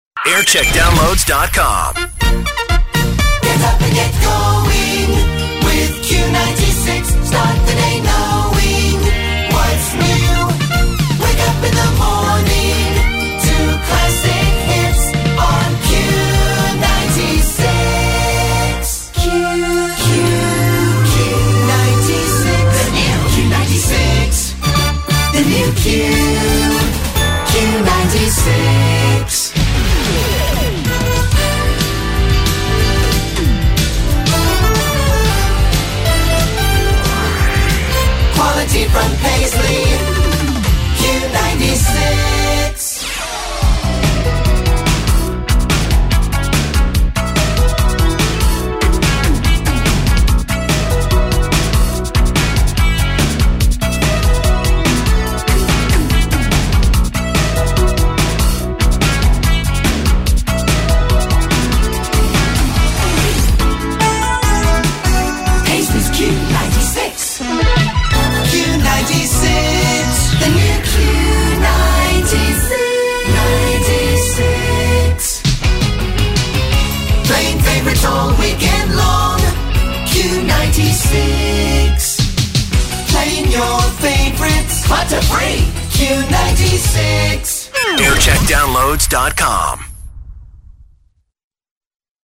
30 Second Jingle Mix